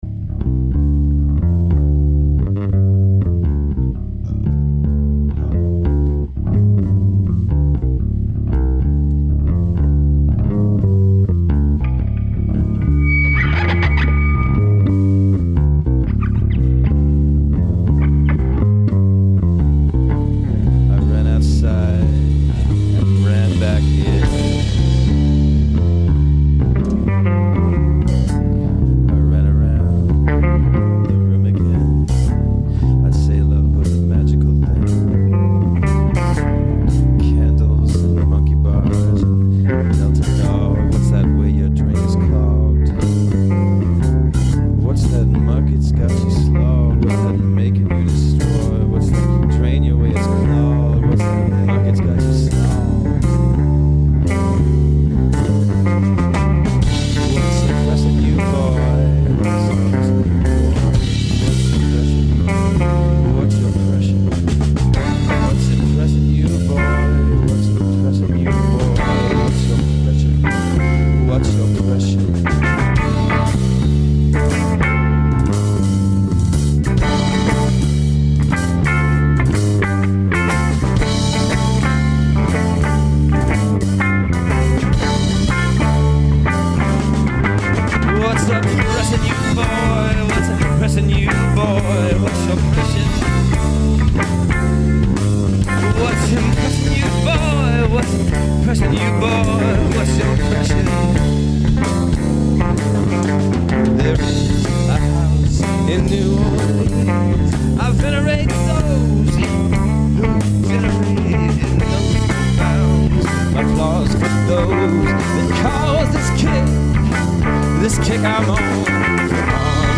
Veneration.  Gem #3 from the Seattle improv sessions.